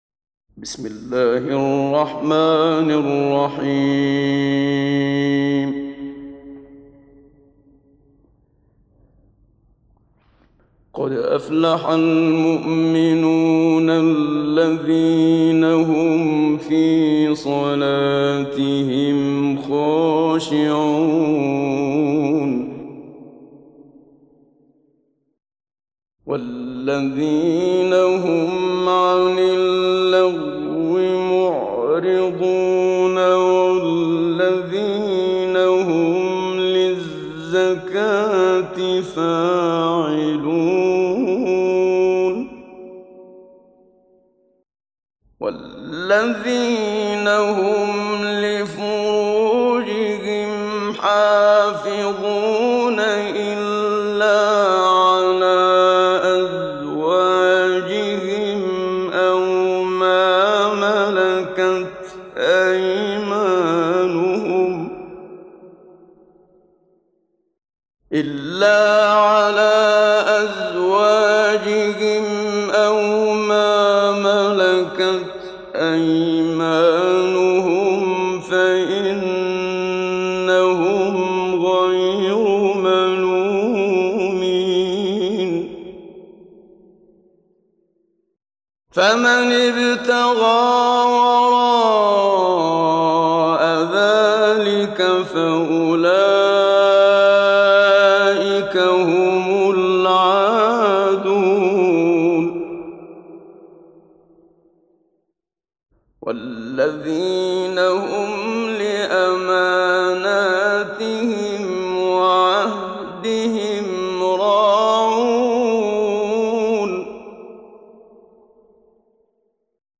Muminun Suresi İndir mp3 Muhammad Siddiq Minshawi Mujawwad Riwayat Hafs an Asim, Kurani indirin ve mp3 tam doğrudan bağlantılar dinle
İndir Muminun Suresi Muhammad Siddiq Minshawi Mujawwad